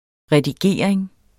Udtale [ ʁεdiˈgeˀɐ̯eŋ ]